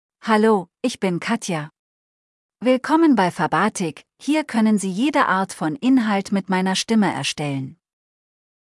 FemaleGerman (Germany)
KatjaFemale German AI voice
Katja is a female AI voice for German (Germany).
Voice sample
Listen to Katja's female German voice.
Female
Katja delivers clear pronunciation with authentic Germany German intonation, making your content sound professionally produced.